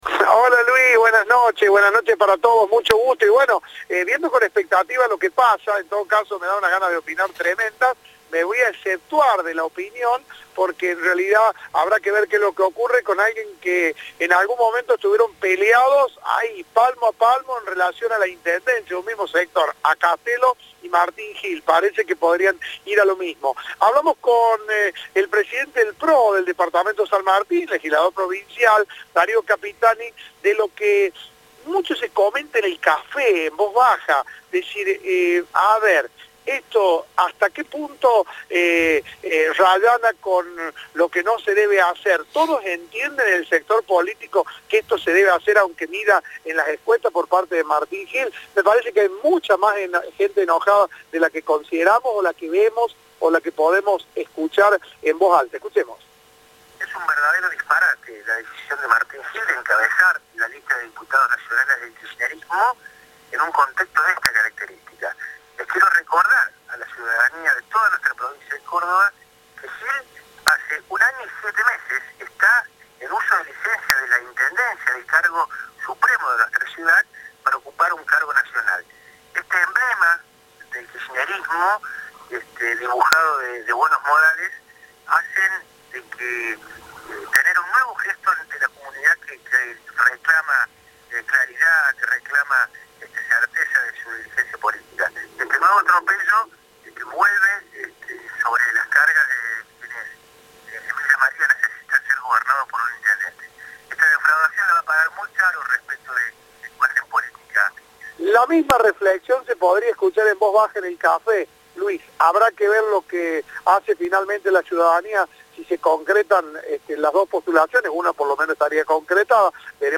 Legislador del PRO cuestionó la candidatura de Martín Gill en el kirchnerismo